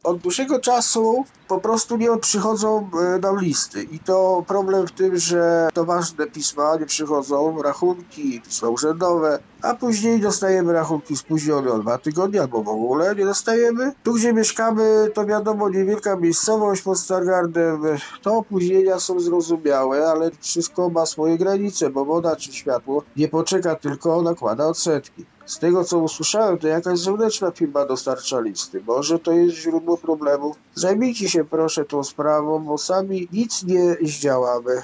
Mówi mieszkaniec Strachocina.